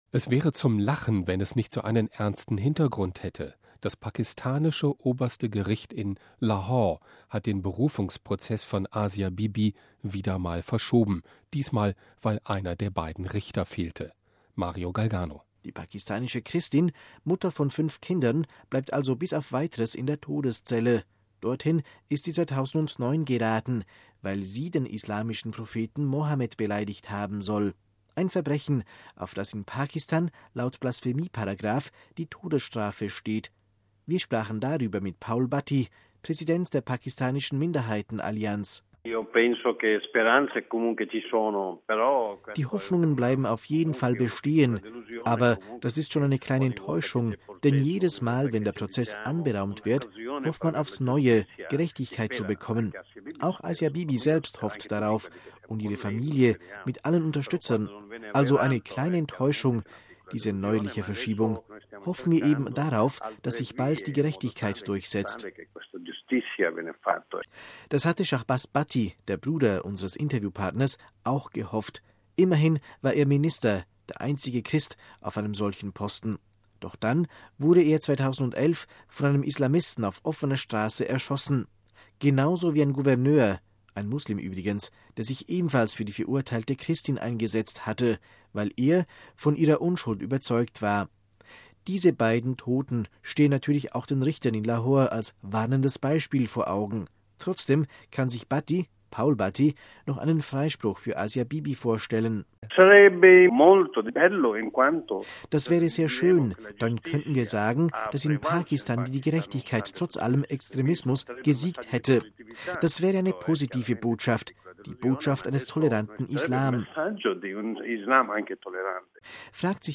Wir sprachen darüber mit Paul Bhatti, Präsident der pakistanischen Minderheiten-Allianz.